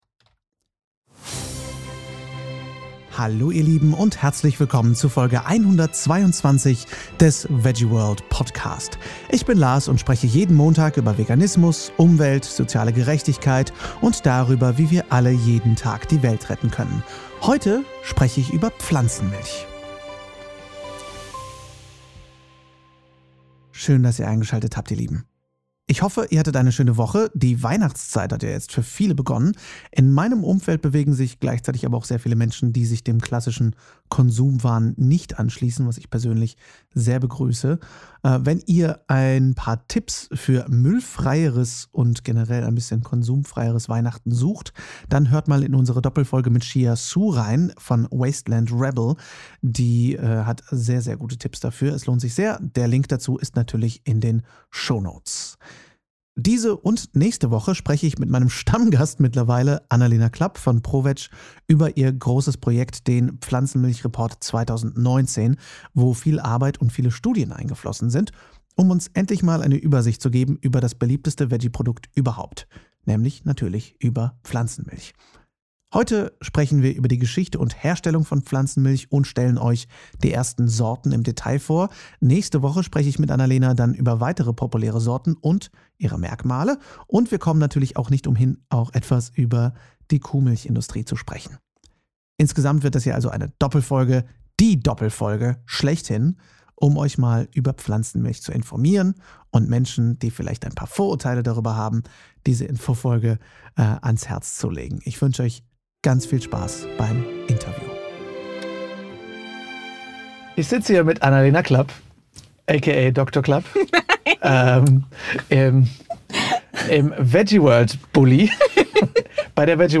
Das und mehr im Interview!